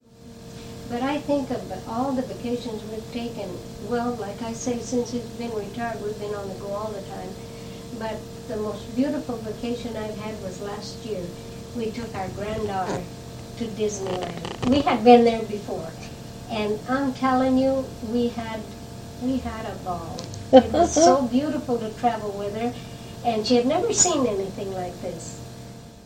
Oral History Clip - Disneyland Trip